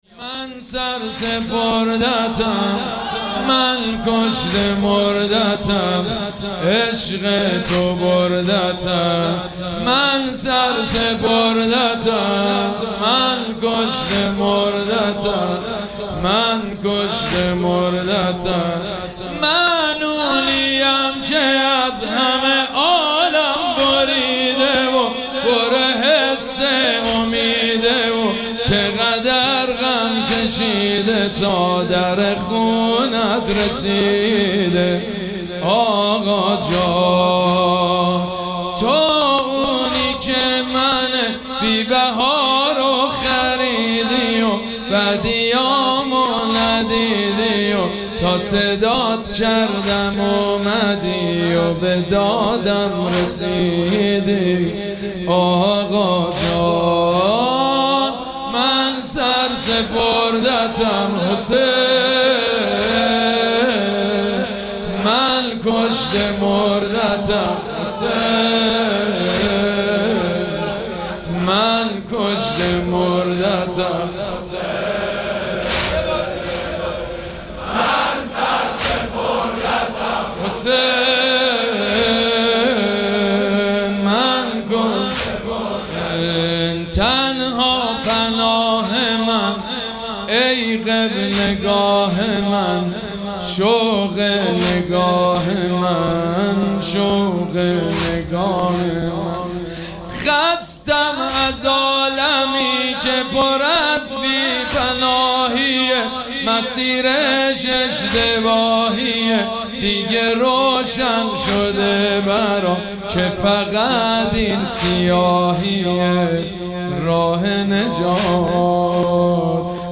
مراسم عزاداری شب نهم محرم الحرام ۱۴۴۷
مداح
حاج سید مجید بنی فاطمه